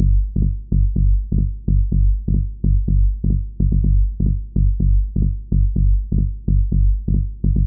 • Tech House Bass Rhythm Punchy - G.wav
Loudest frequency 82 Hz
Tech_House_Bass_Rhythm_Punchy_-_G_xVF.wav